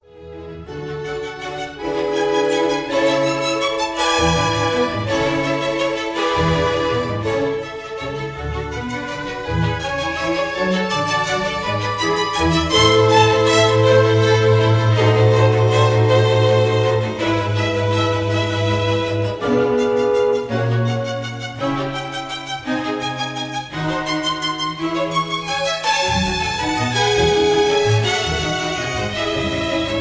Recorderd live at the 1st Aldeburgh
Jubilee Hall, Aldeburgh, Suffolk